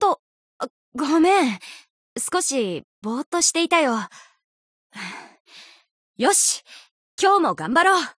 贡献 ） 协议：Copyright，作者： Cygames ，其他分类： 分类:富士奇石语音 您不可以覆盖此文件。